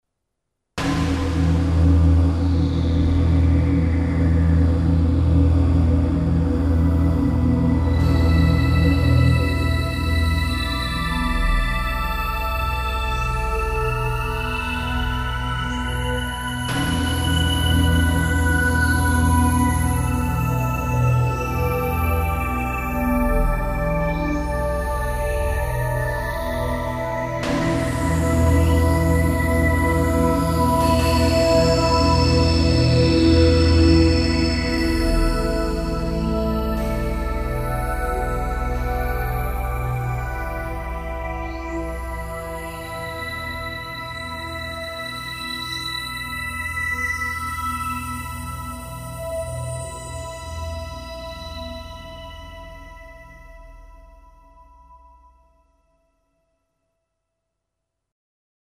在键盘上一阵乱弹琴。
然后我们换一个音色，从头来，跟着刚才录的东西，把第二轨加进去。
听听加上第二轨的声音后的样子吧：